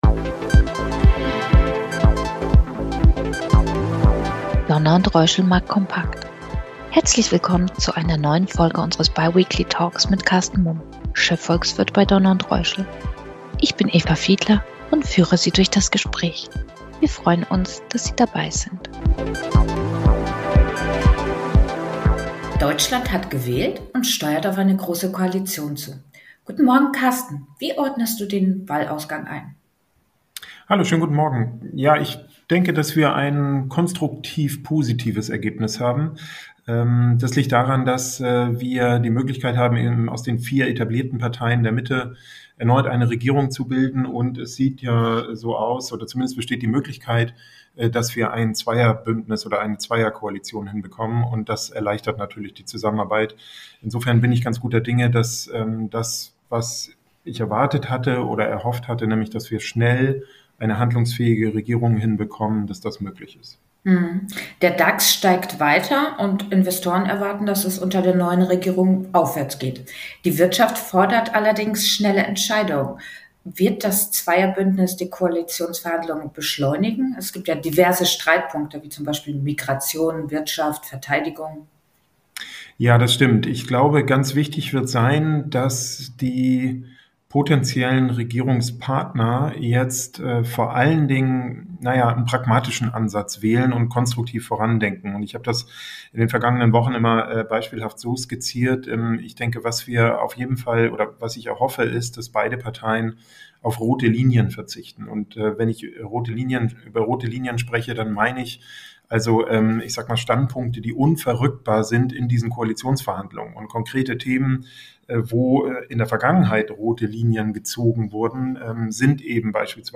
Der neue wöchentliche Talk zu aktuellen Marktthemen und einem Ausblick auf die globalen Kapitalmärkte.